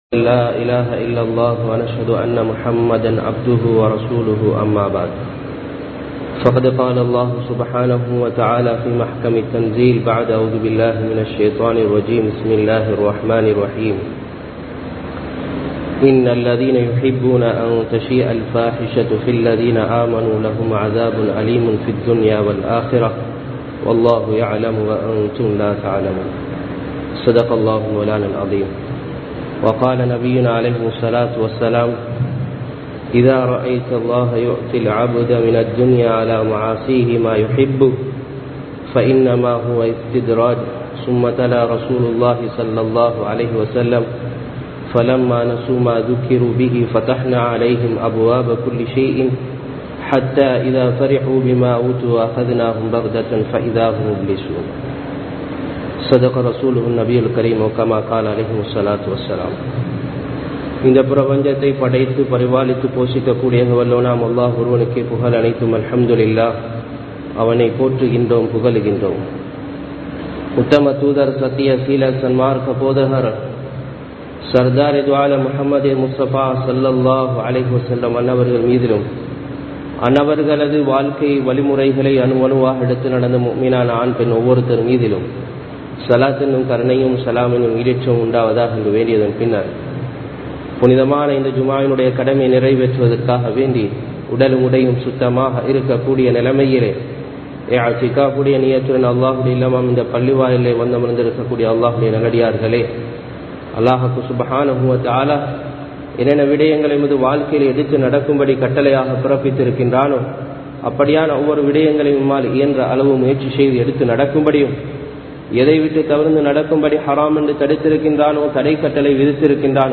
பொருட்களின் விலை ஏற்றமா? | Audio Bayans | All Ceylon Muslim Youth Community | Addalaichenai
Town Jumua Masjidh